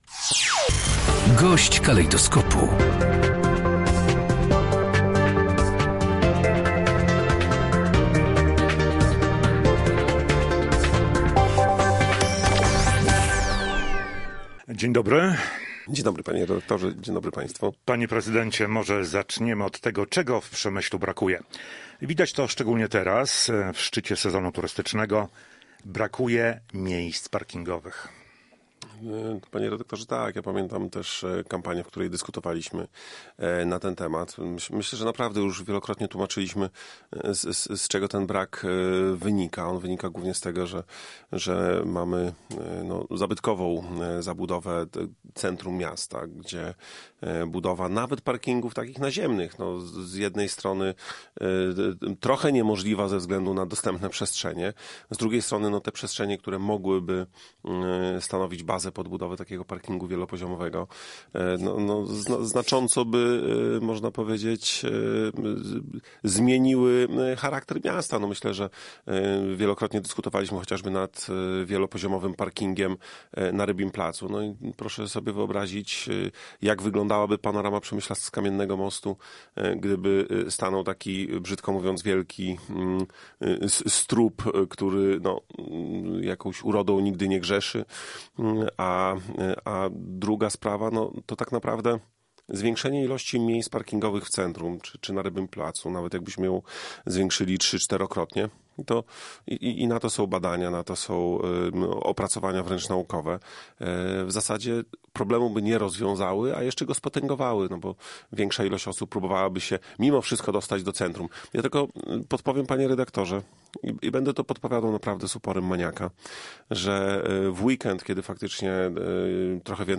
GOŚĆ DNIA. Wojciech Bakun, prezydent Przemyśla